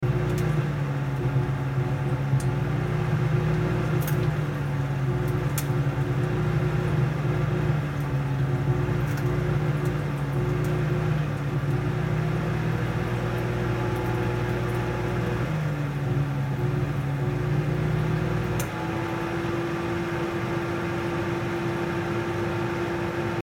Broken microwave
The last days of a badly designed microwave oven.